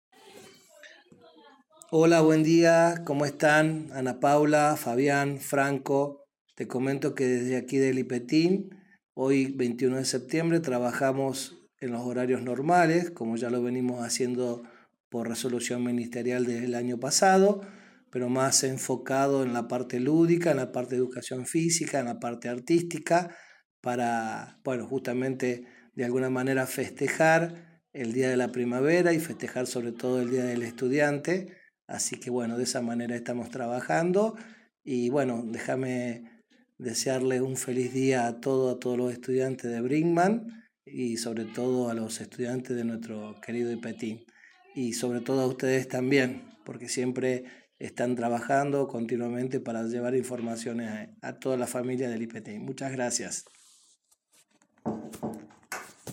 En diálogo con LA RADIO 102.9 FM los directores de las dos escuelas secundarias de la ciudad contaron que los estudiantes tendrán un cambio de rutina con diferentes actividades referidas al día de la fecha.